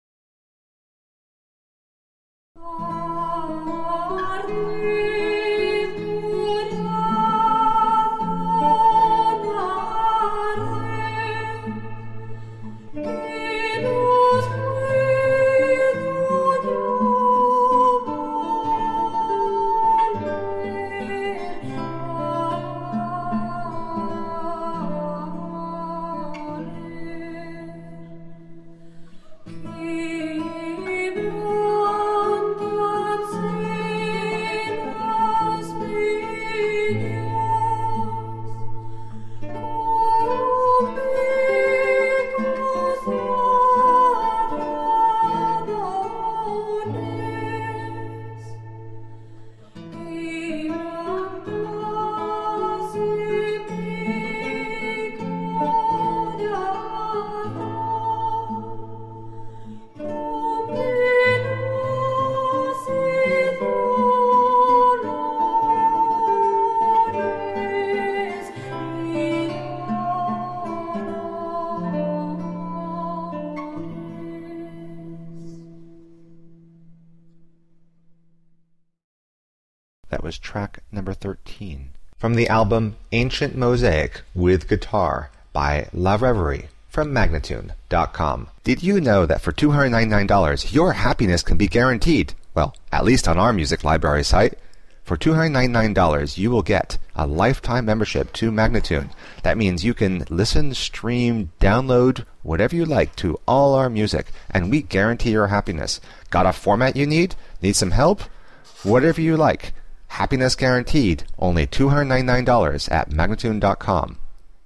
Recreating traditional music from a modern perspective.
Classical, Renaissance, Baroque, Classical Singing
Classical Guitar